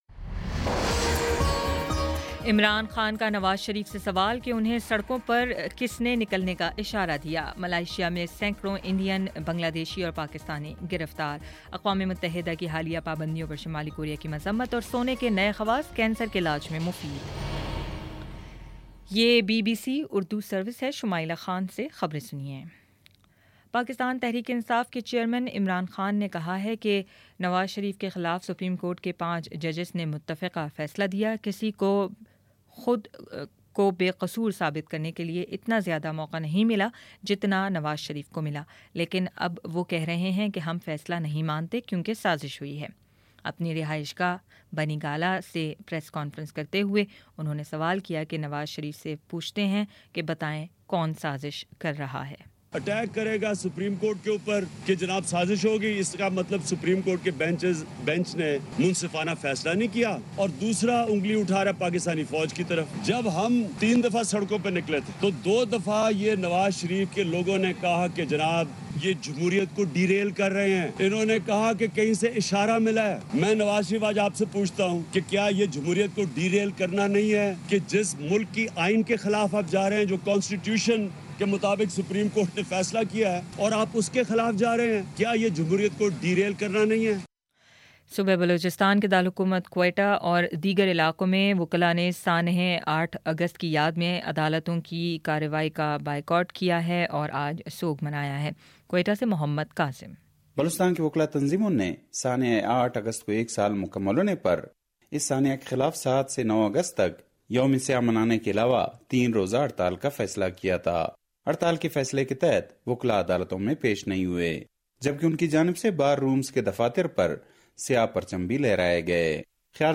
اگست 07 : شام سات بجے کا نیوز بُلیٹن